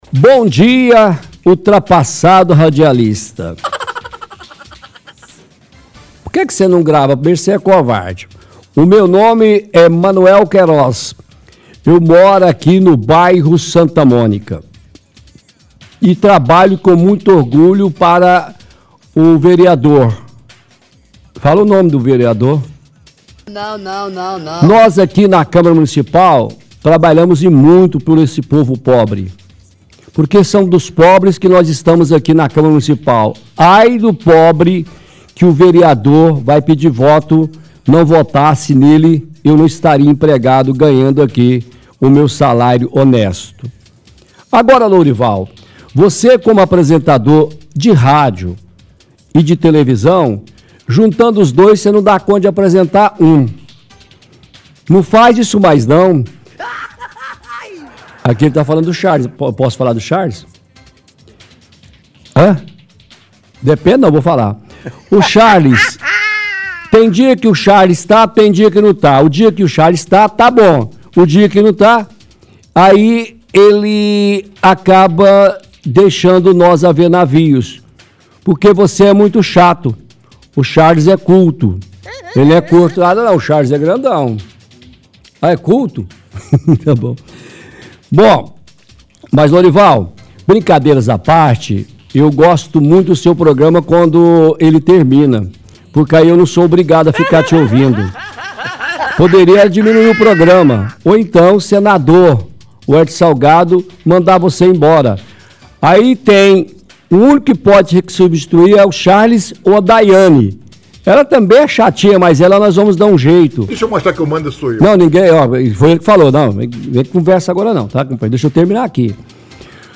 lê mensagem de ouvinte fazendo criticas ao programa